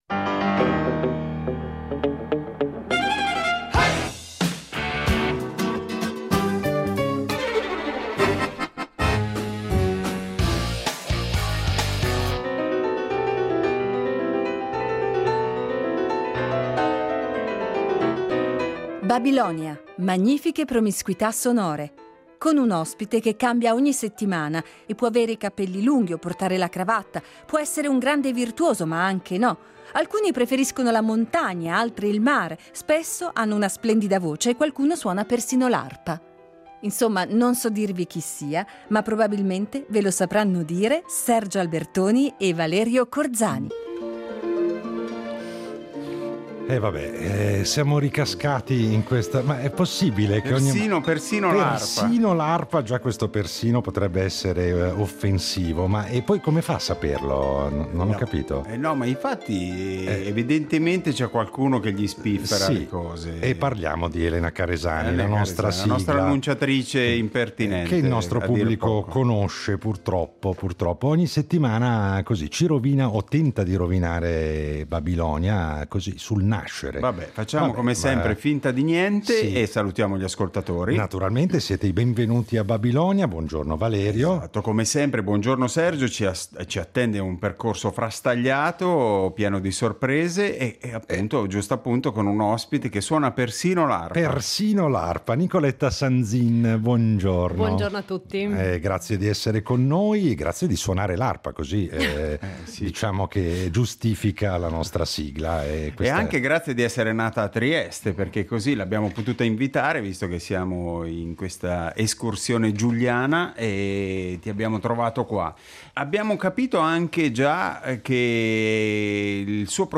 È sempre un grande piacere poter accogliere sulla nostra arca radiofonica un’arpista, e forse non è un caso se succede spesso e volentieri.